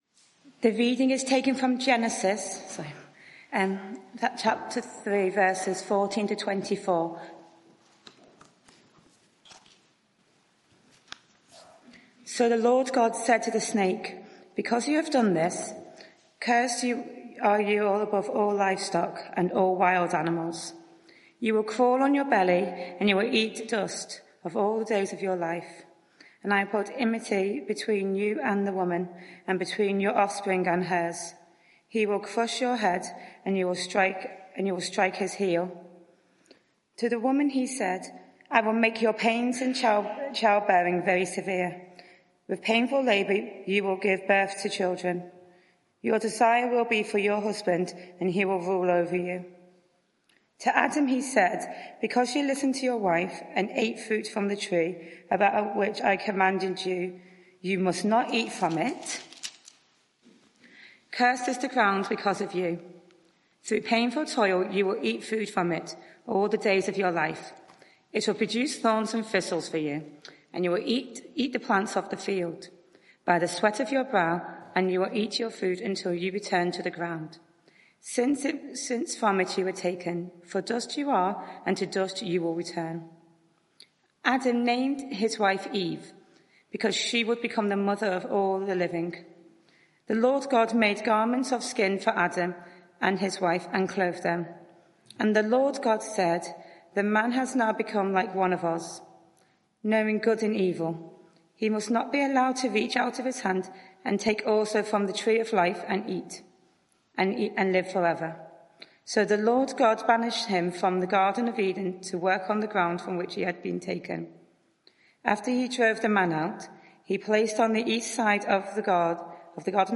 Media for 6:30pm Service on Sun 10th Nov 2024 18:30 Speaker
Sermon (audio) Search the media library There are recordings here going back several years.